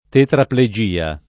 [ tetraple J& a ]